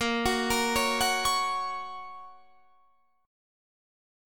A#m#5 Chord
Listen to A#m#5 strummed